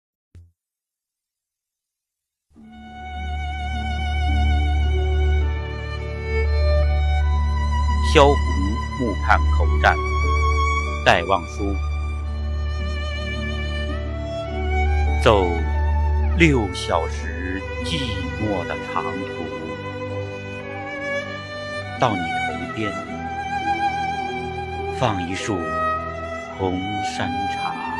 九年级语文下册3《萧红墓畔口占》男声配乐朗读（音频素材）